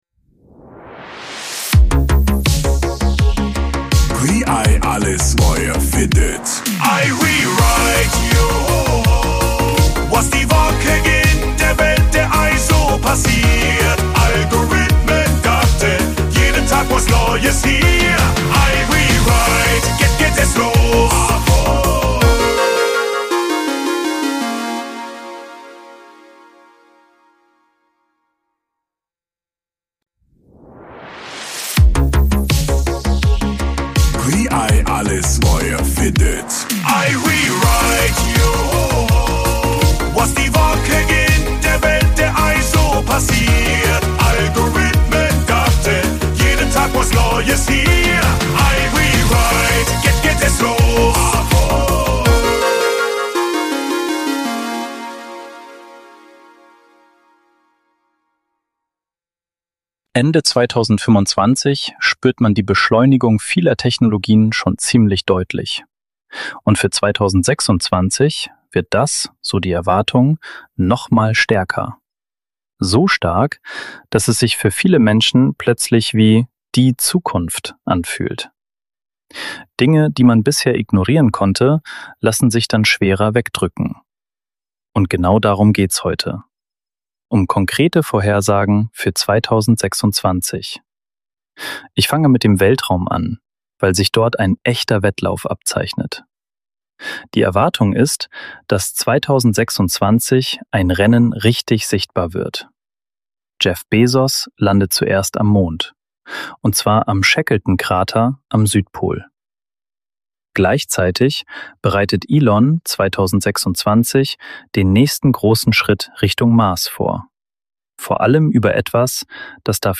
Mal spreche ich , mal meine digitale Stimme.